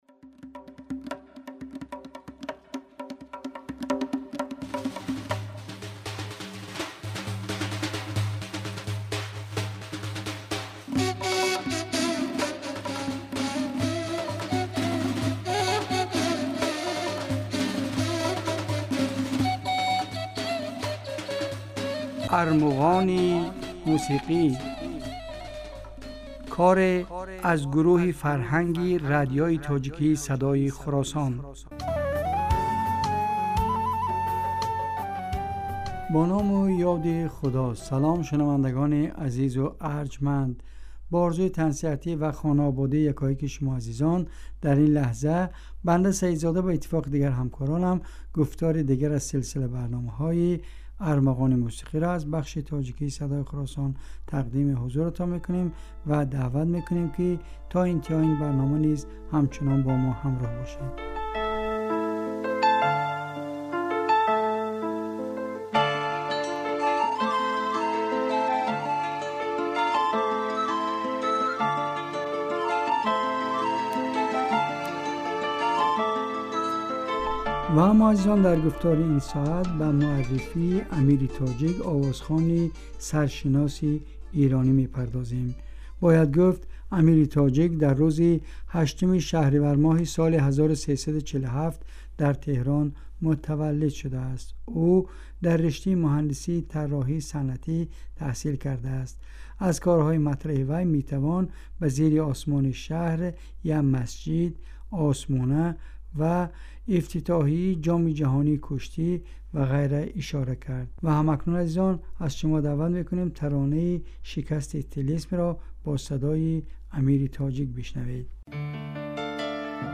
Армуғони мусиқӣ асари аз гурӯҳи фарҳанги радиои тоҷикии Садои Хуросон аст.
Дар ин барномаҳо кӯшиш мекунем, ки беҳтарин ва зеботарин мусиқии тоҷикӣ ва эрониро ба шумо пешкаш кунем.